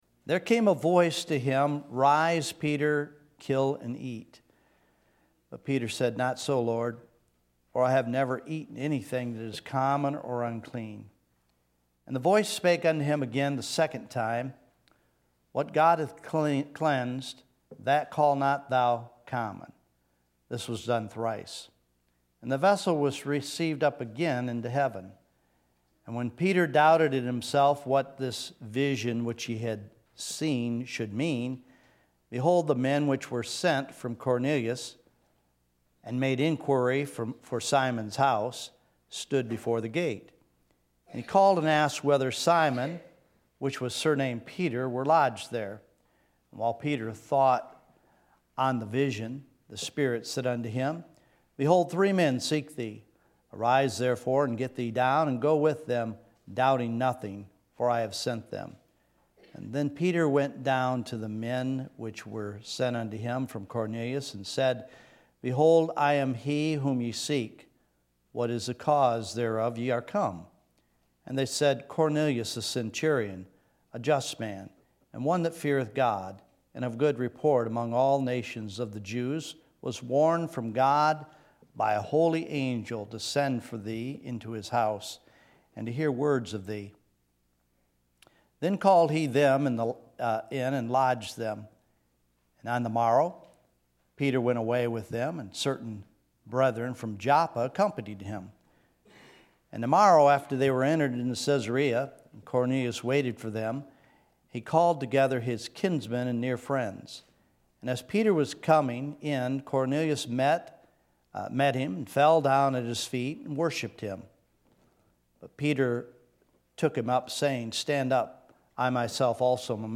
Sermons | Buckley Road Baptist Church
Buckley Road Baptist Church - Sunday Evening Service